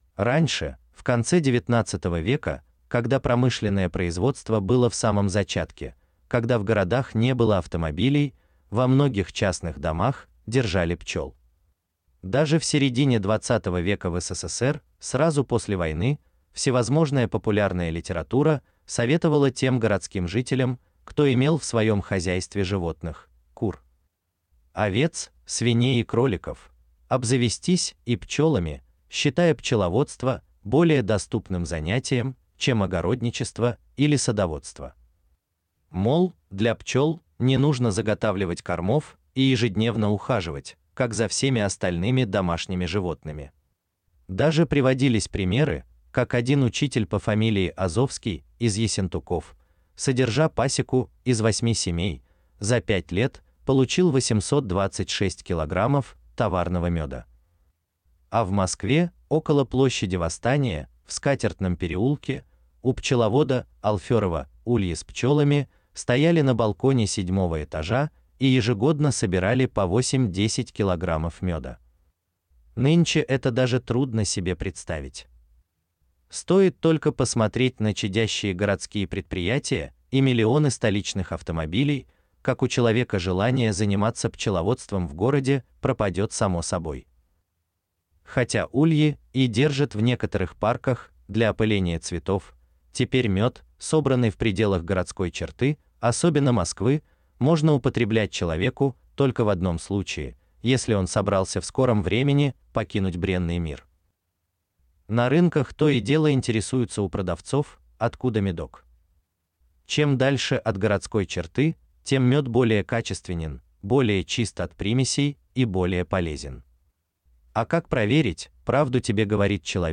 Аудиокнига Пчеловодство для начинающих. Практическое пошаговое руководство по созданию пасеки с нуля | Библиотека аудиокниг
Читает аудиокнигу Искусственный интеллект